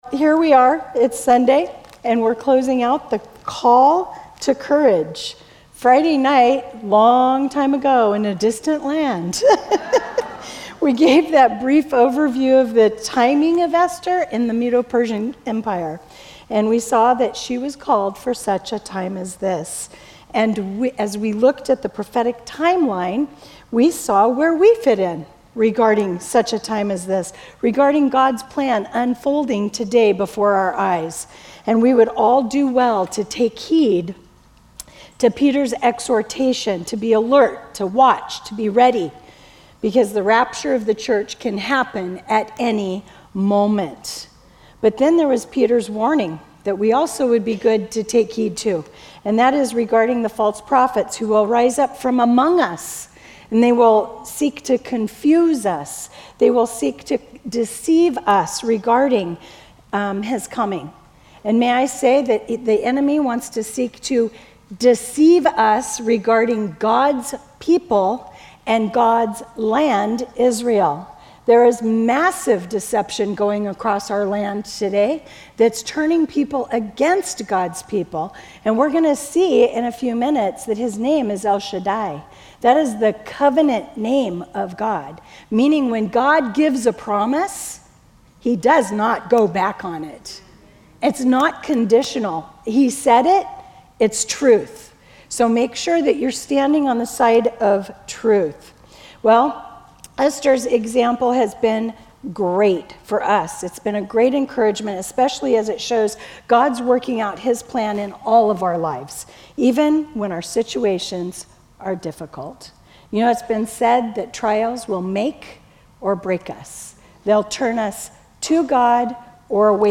Women's Retreat in Phoenix Arizona in 2025